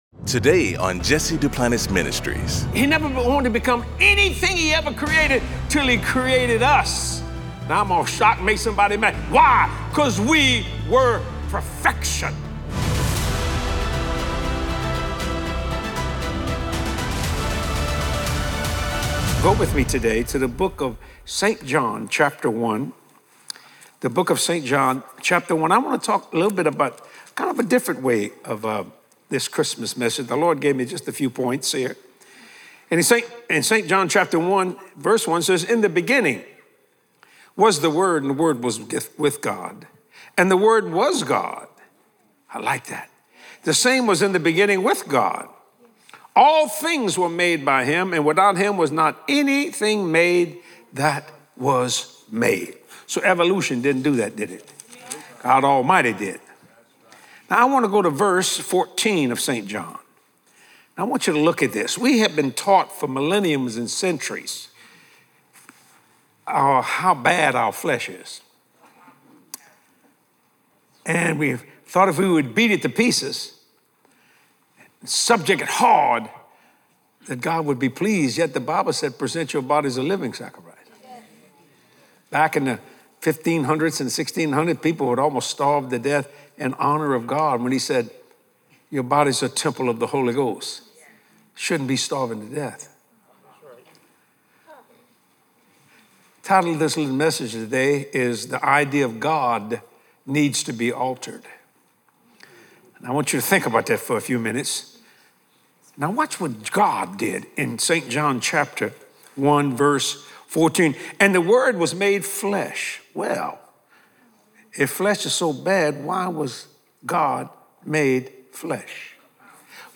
In this inspiring Christmas message